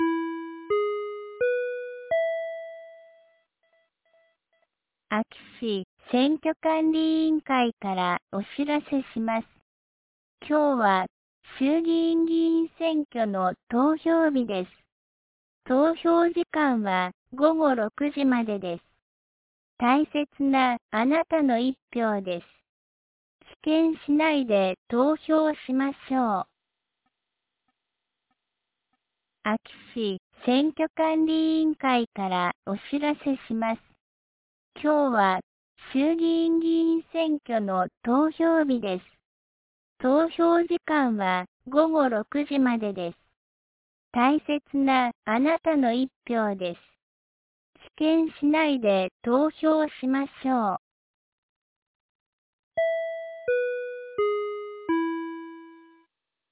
2026年02月08日 17時41分に、安芸市より全地区へ放送がありました。